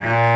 Cello(3)_A#2_22k.wav